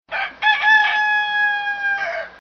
Category: Animal Ringtones